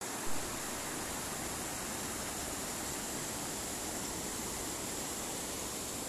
Sonidos del Pura Vida | BioSonidos - Colección de Sonidos de la Naturaleza
Canto del Río Savegre SAN JOSÉ